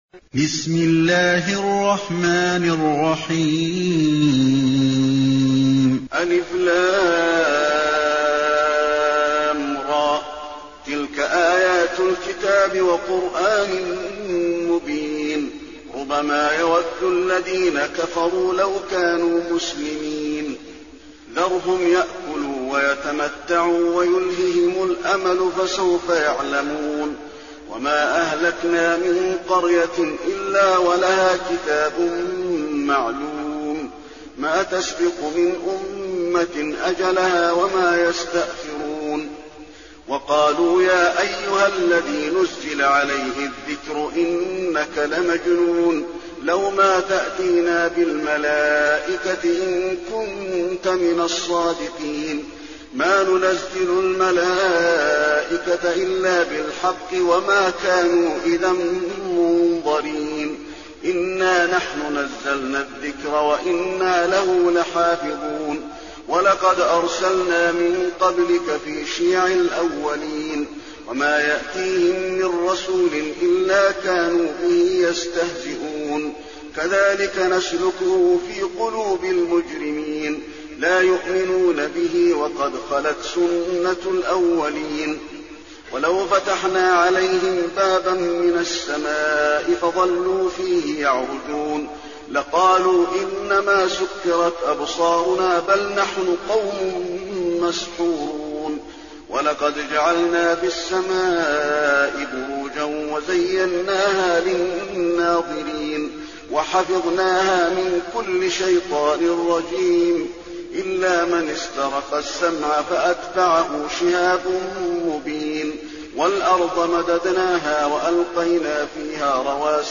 المكان: المسجد النبوي الحجر